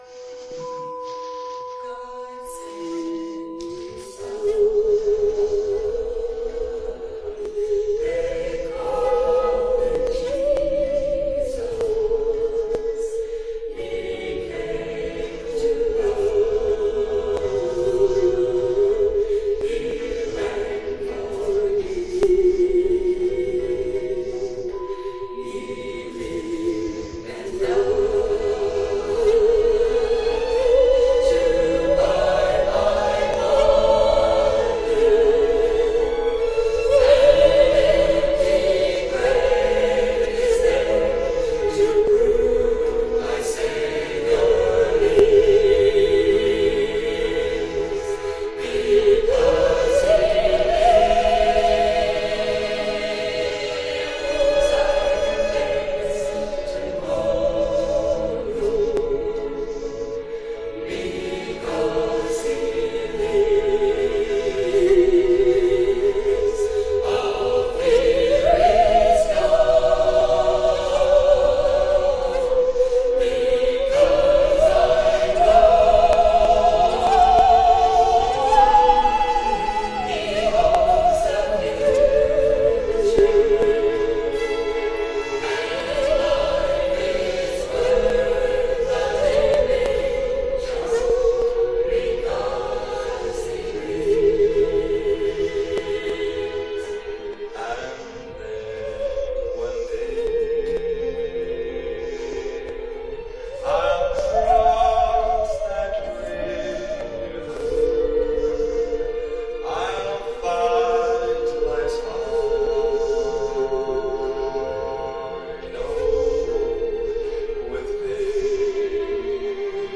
Special performances
btn SP-1629-2 Hymn 526 Dudley House 16th July 2016